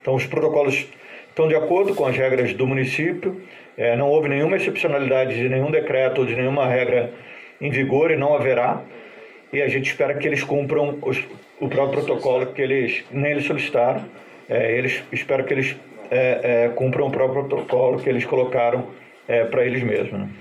Daniel Soranz falando sobre a Conmebol durante a divulgação do boletim epidemiológico
O secretário municipal de saúde, Daniel Soranz, falando a respeito da realização de jogos da Copa América, no Rio de Janeiro, disse que a Conmebol entrou em contato oficialmente com a prefeitura e os jogos, sem a presença de público, estão liberados, pois a confederação Sul-Americana apresentou protocolos sanitários que estão dentro das regras do município.